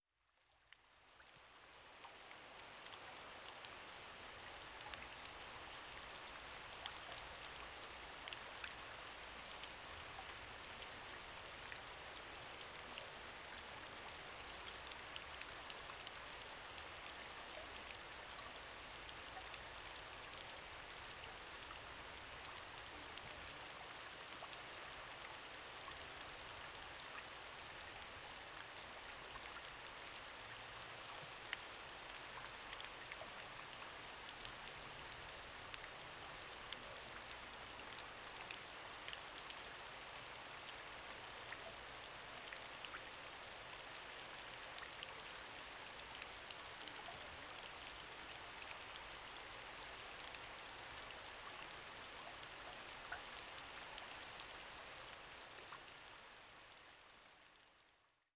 Rain1.wav